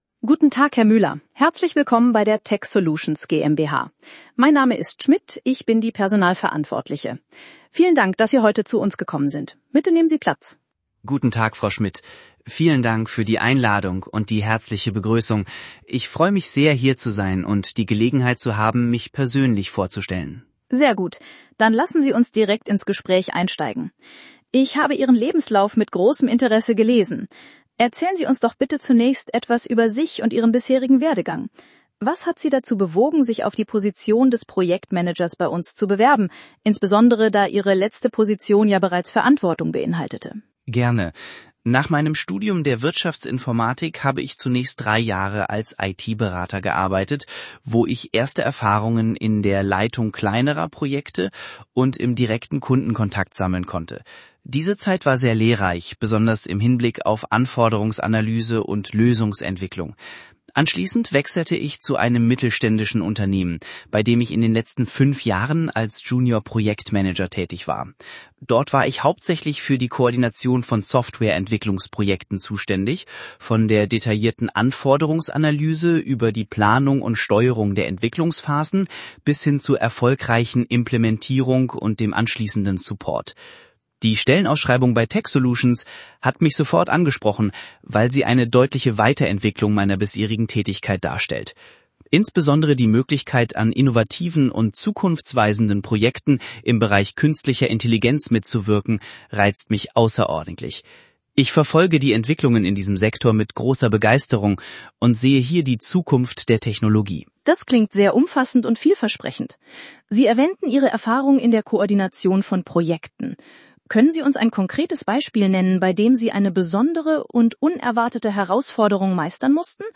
Bewerbugsgespraech-B2-Dialog.mp3